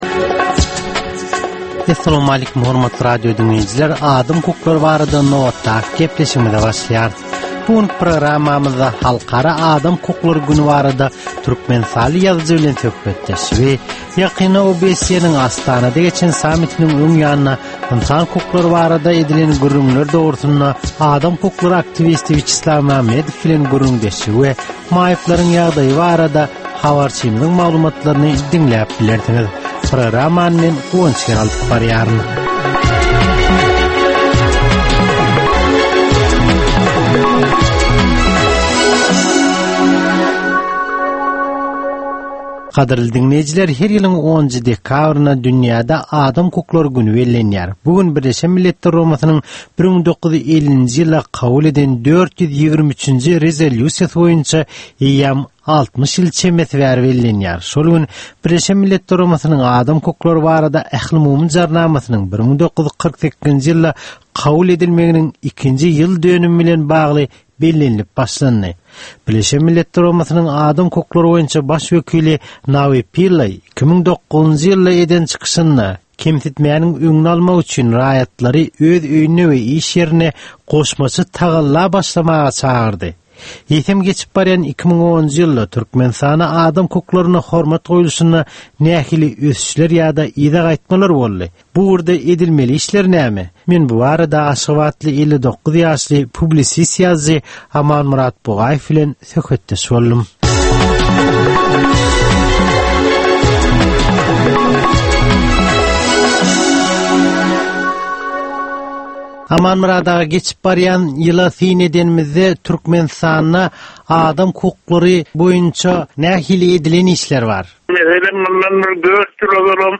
Türkmenistandaky adam hukuklarynyň meseleleri barada ýörite programma. Bu programmada adam hukuklary bilen baglanyşykly anyk meselelere, problemalara, hadysalara we wakalara syn berilýär, söhbetdeşlikler we diskussiýalar gurnalýar.